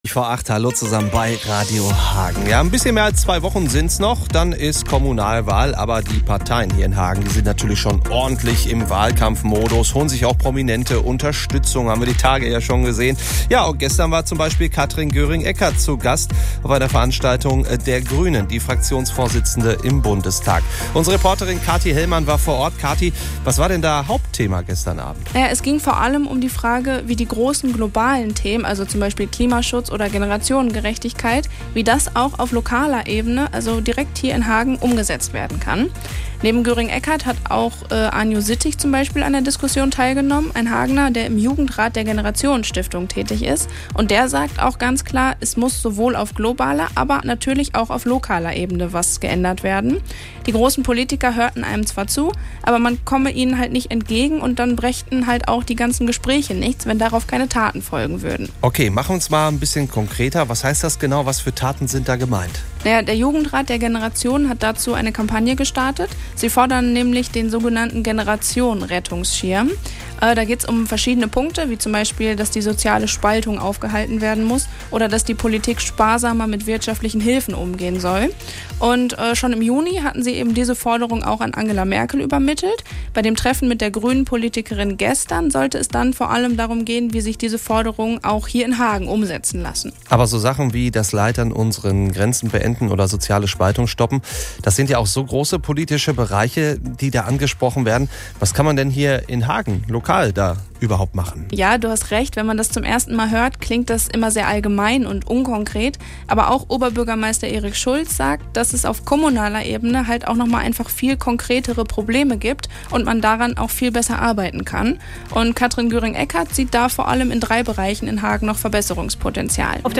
Klimawandel und Generationengerechtigkeit - das war gestern Abend Thema bei einer Diskussionsrunde, zu der die Grünen in Hagen eingeladen hatten.